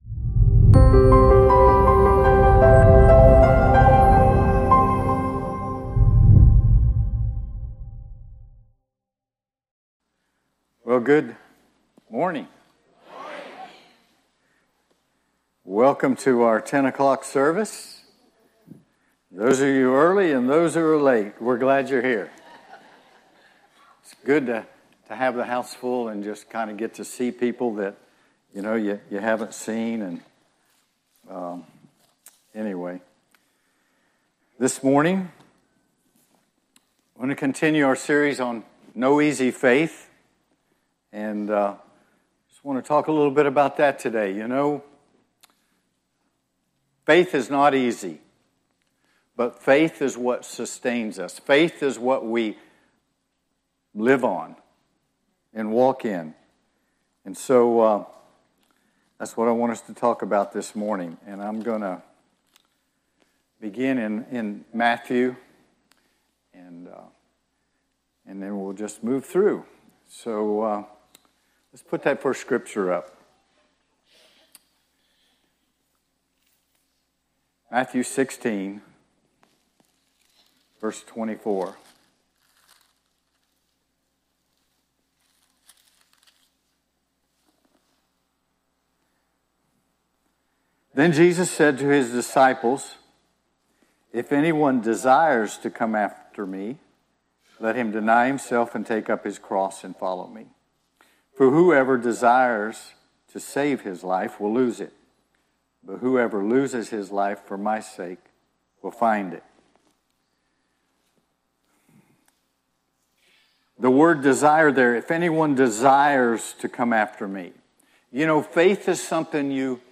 2025_07_06-NO-EASY-FAITH-Part-8-Heartcry-Chapel-Sunday-Sermon.mp3